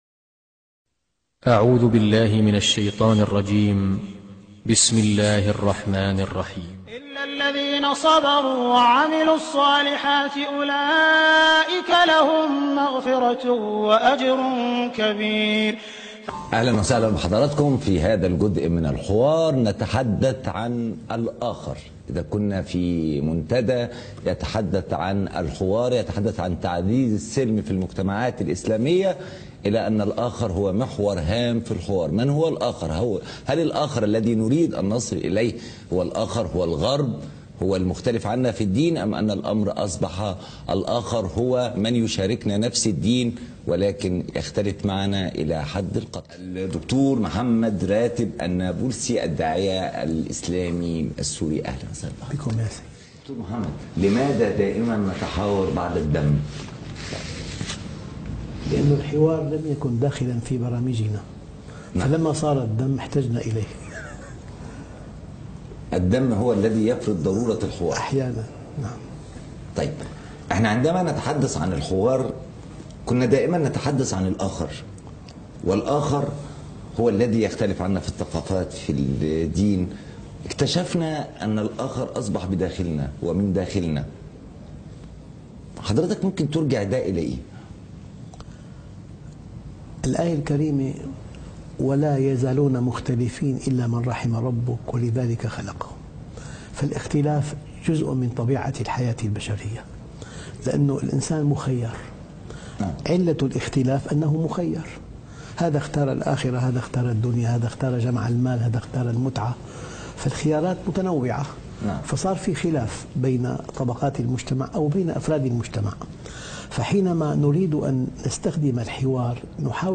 الحوار وأهميته في المجتمعات - لقاء وحوار خاص - الشيخ محمد راتب النابلسي